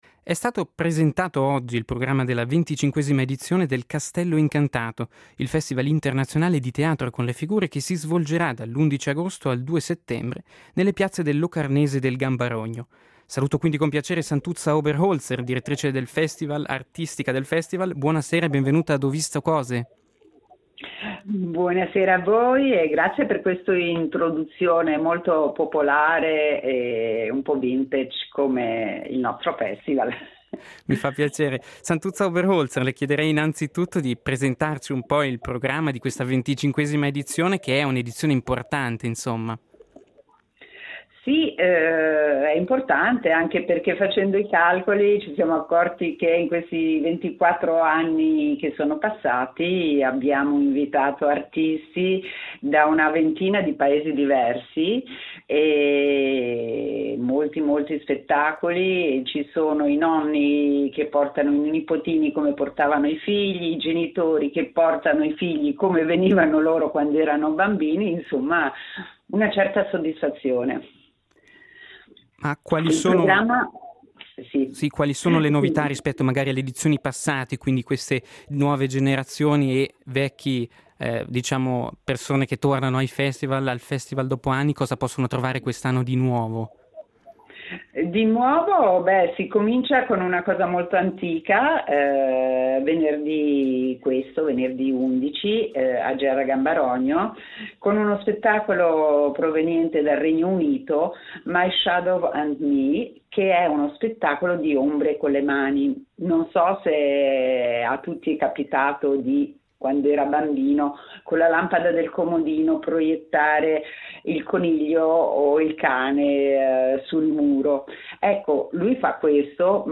Ai blocchi di partenza l’edizione 2023 del Castello Incantato, il Festival internazionale di teatro con le figure che si svolgerà dall’11 agosto al 2 settembre nelle piazze del Locarnese e del Gambarogno. L’intervista